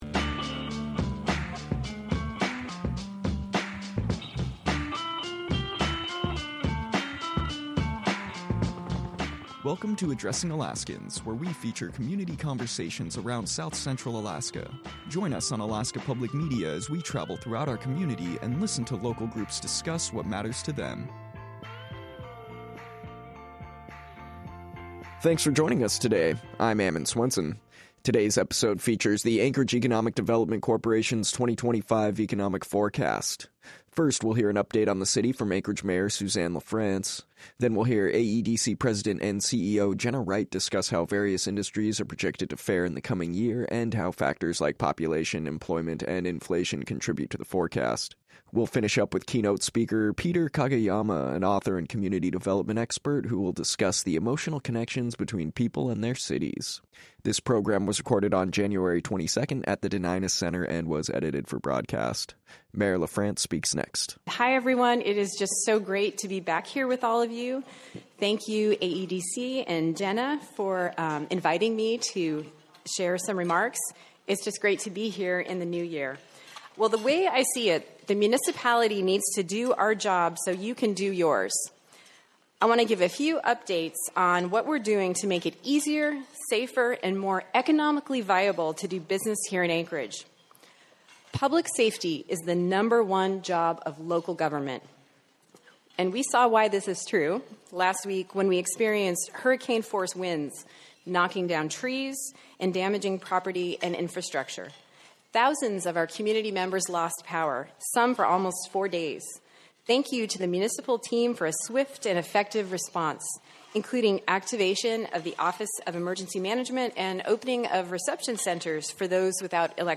1 Hear the Anchorage Economic Development Corporation's 2025 forecast | Addressing Alaskans 58:58 Play Pause 5d ago 58:58 Play Pause Main Kemudian Main Kemudian Senarai Suka Disukai 58:58 This episode features the Anchorage Economic Development Corporations 2025 economic forecast. First, we hear an update on the city from Anchorage Mayor Suzanne LaFrance.